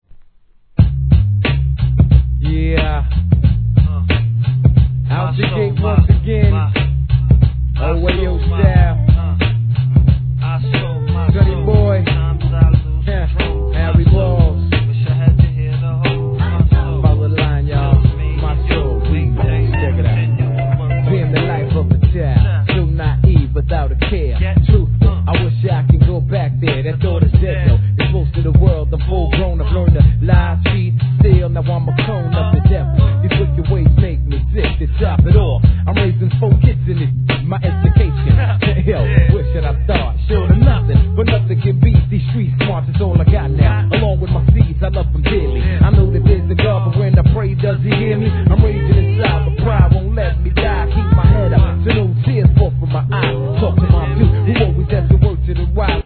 HIP HOP/R&B